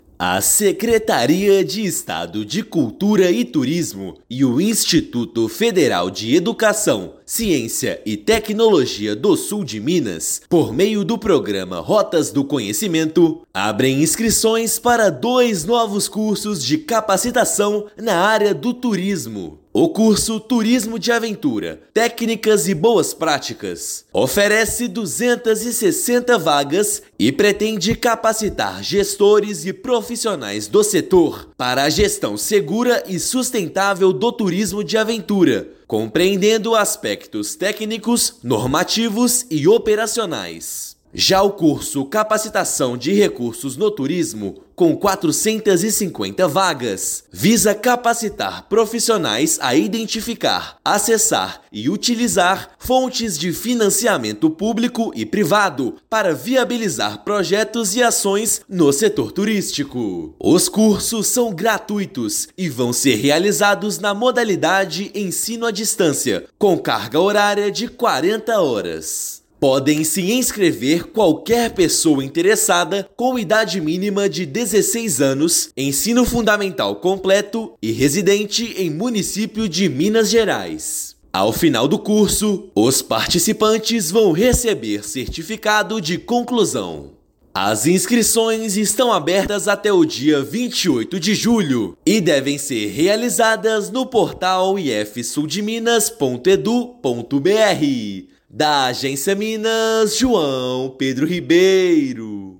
Ao todo, serão 710 vagas com inscrições gratuitas; aulas serão realizadas na modalidade Ensino a Distância. Ouça matéria de rádio.